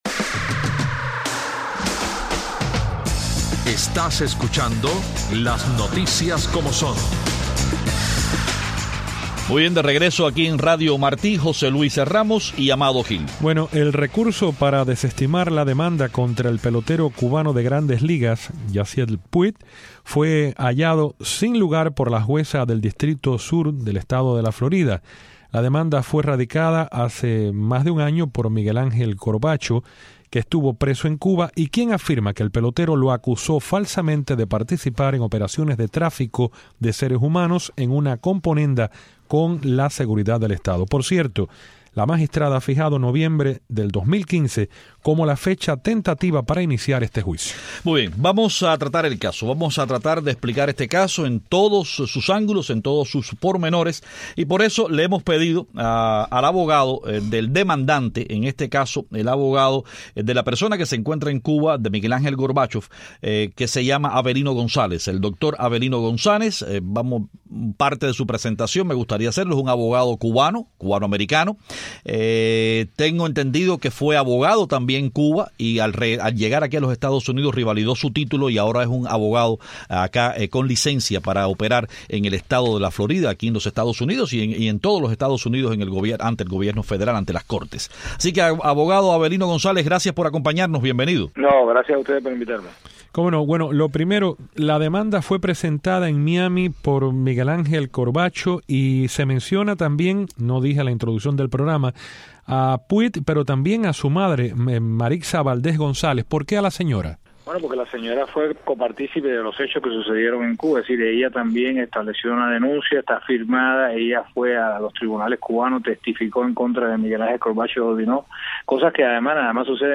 Hoy la invitamos al programa para conocer los nuevos detalles del caso.